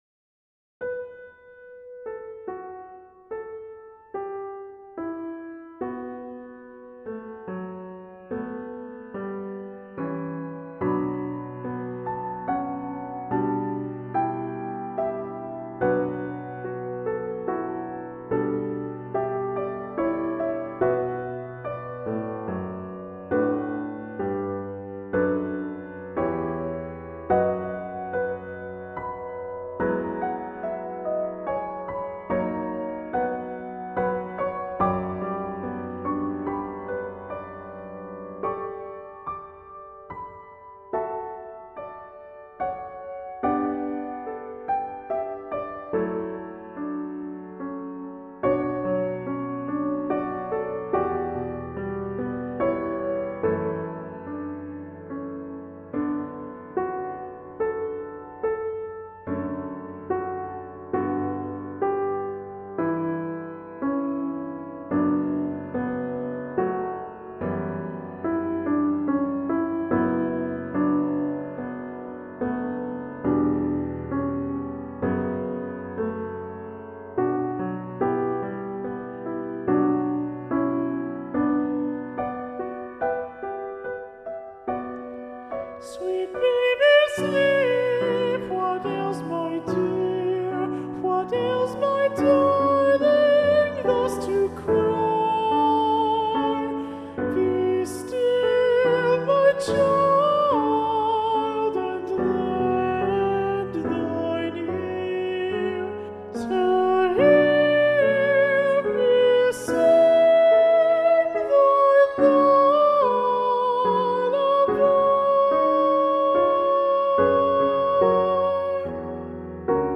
Choral Music